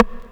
RIM 12    -R.wav